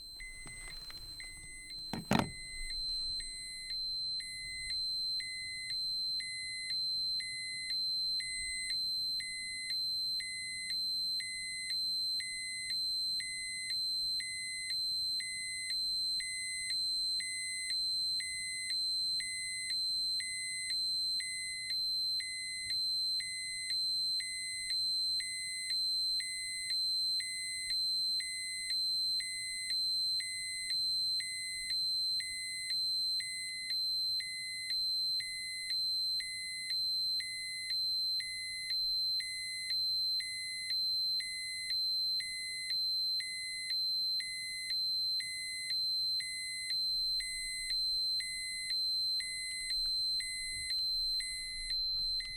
Sound Effect
Dosimeter Alarm
Dosimeter_Alarm.mp3